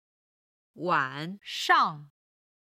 晚上　(wǎn shàng)　太陽が沈むころから夜半
07-wan3shang4.mp3